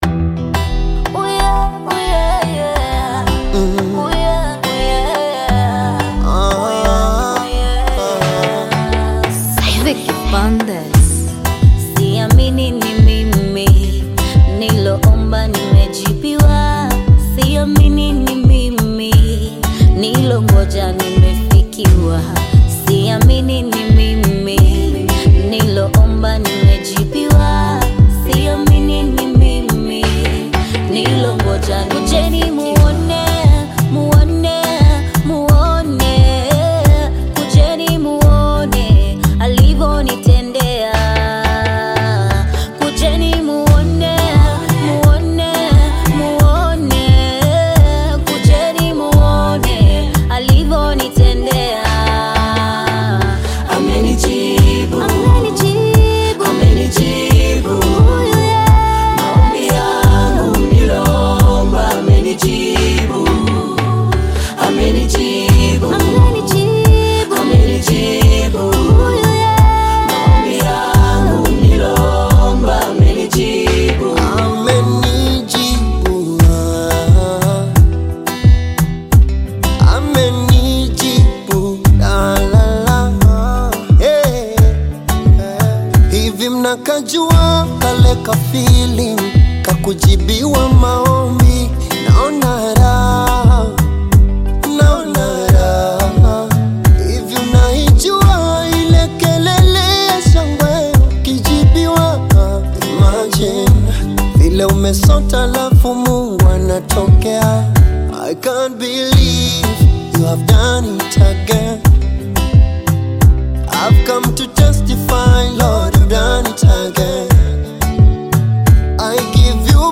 Gospel music track
Kenyan gospel artist
Gospel song
This catchy new song